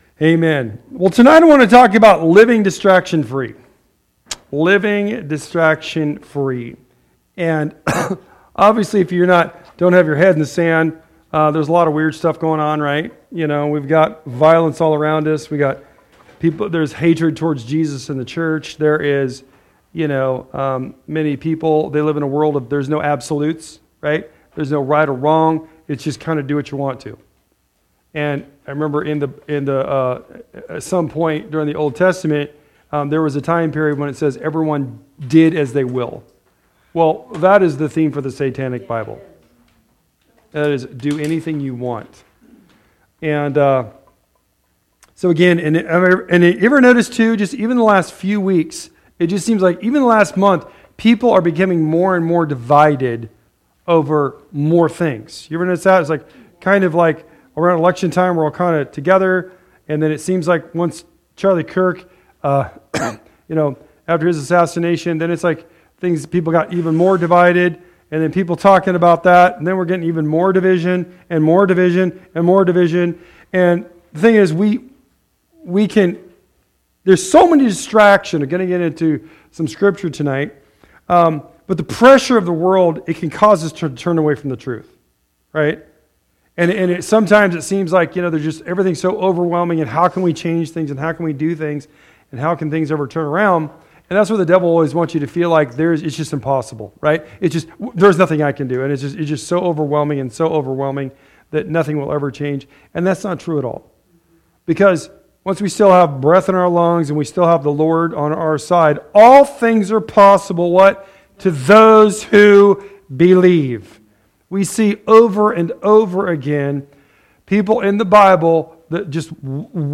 Weekly messages/sermons